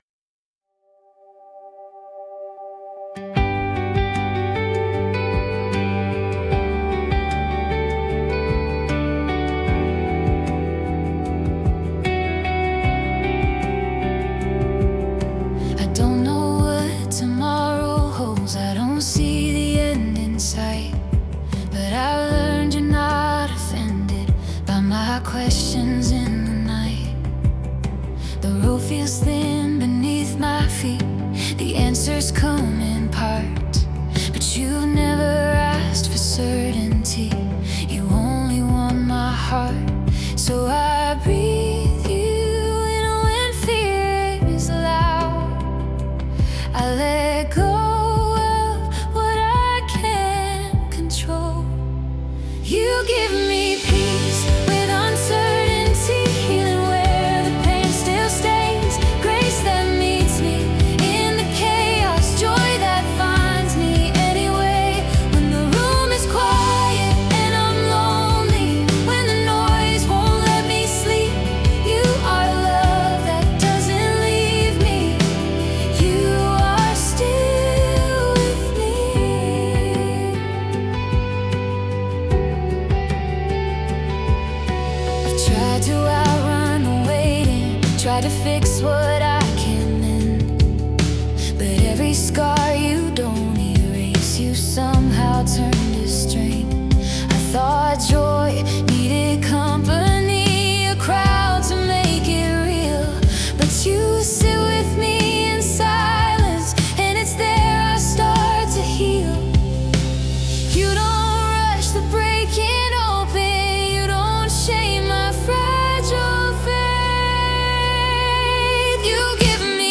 (Drop instruments. Piano pad only. Almost whispered.)
(Drums swell. Choir pad rises. One beat of silence.)
So I put that into a music generator and here is the result:
Yea, it’s a woman’s voice but that seems right.